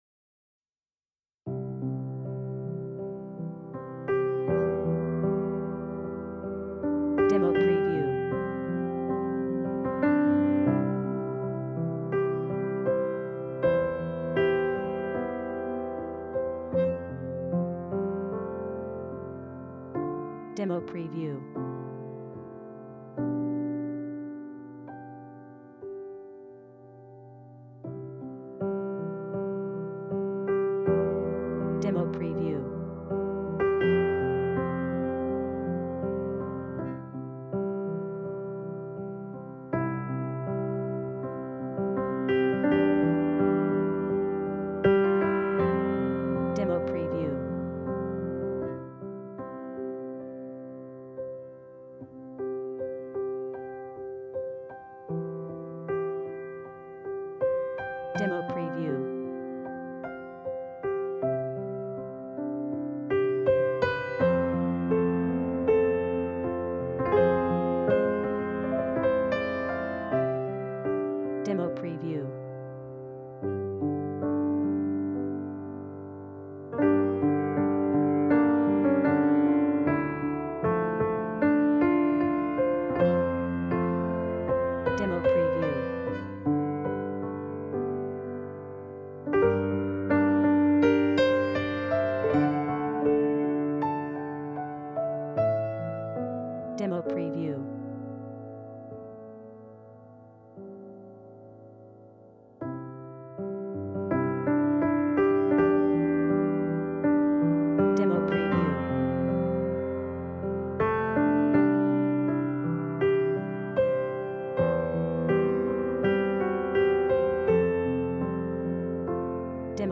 מוסיקה שכתבתי לוידאו שמתאר התחלה של יום גשום מאד...